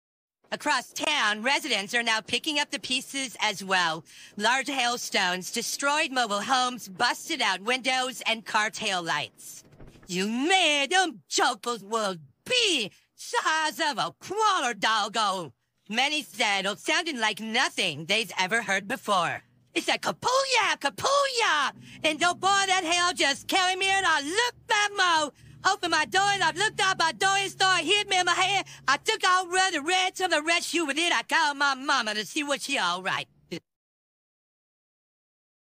AI VOICE